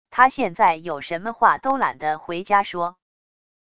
她现在有什么话都懒得回家说wav音频生成系统WAV Audio Player